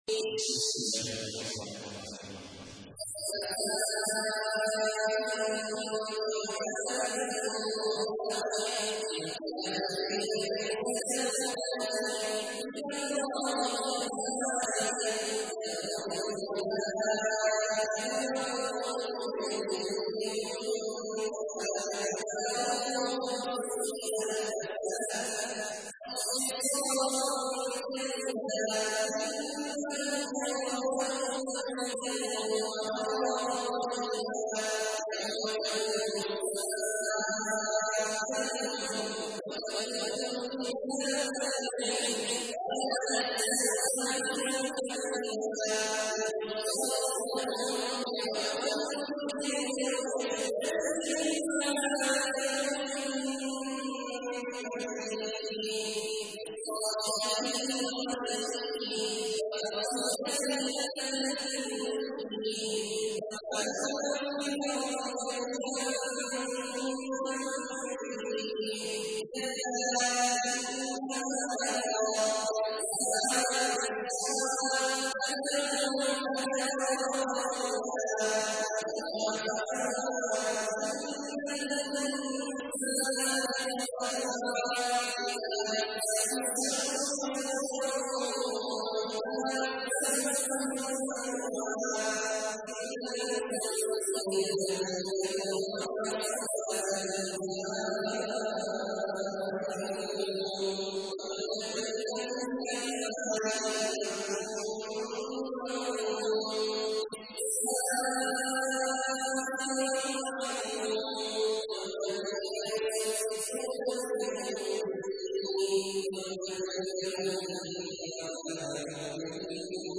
تحميل : 70. سورة المعارج / القارئ عبد الله عواد الجهني / القرآن الكريم / موقع يا حسين